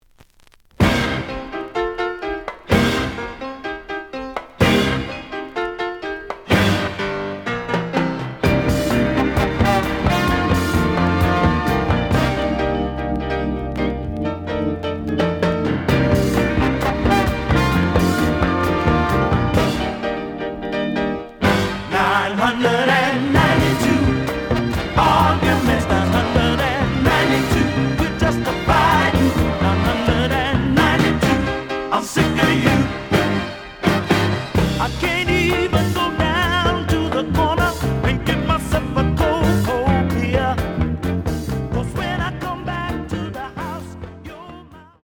The audio sample is recorded from the actual item.
●Genre: Soul, 70's Soul
●Record Grading: VG~VG+ (傷はあるが、プレイはおおむね良好。Plays good.)